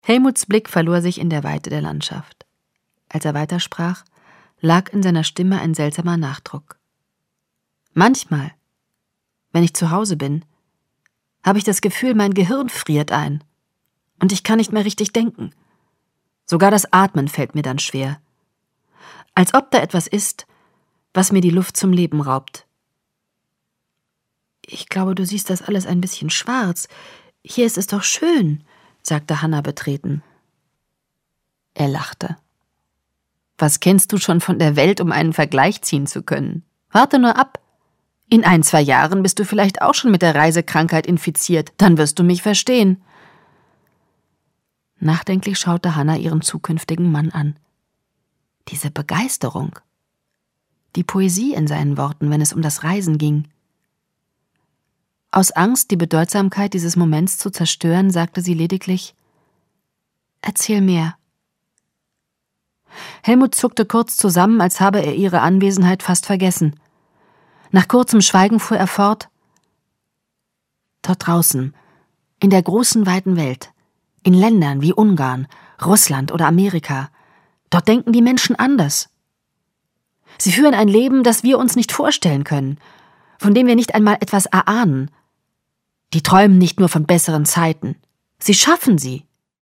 Ulrike Grote (Sprecher)
Jahrhundert • AUDIO/Belletristik/Erzählende Literatur • AUDIO/Belletristik/Historische Romane, Erzählungen • Audio-CD • Durst Benning • Frauenroman • Gier • Hass • Historische Romane/Erzählungen • Historischer Roman • Hörbuch • Hörbücher • Hörbuch; Literaturlesung • Hörbuch; Literaturlesungen • Hörbuch; Literaturlesungen 345016 • Hörbuch; Literaturlesungen (Audio-CDs) • Liebe • Literaturlesungen (Audio-CDs) • Melodram • Neid • Starke Frauen • TV-Verfilmung